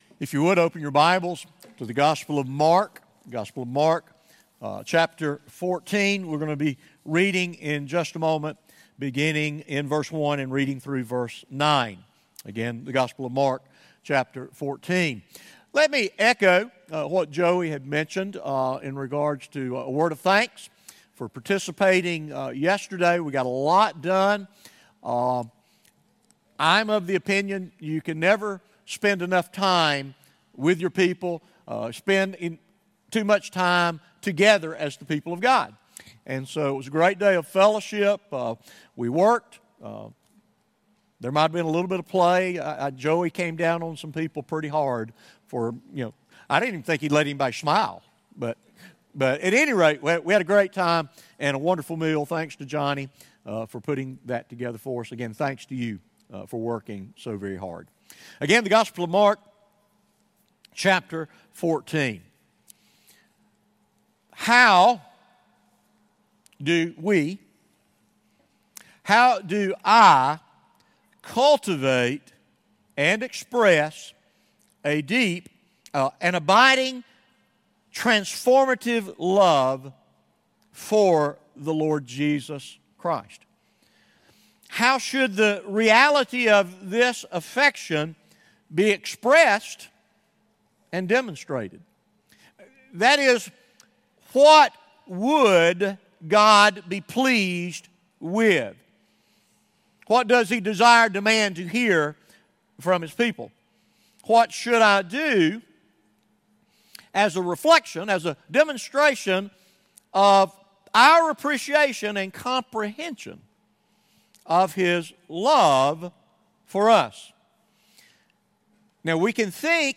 Sunday Sermons from the pastoral staff at North Clay Baptist Church.